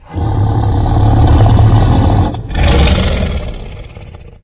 giant_idle_1.ogg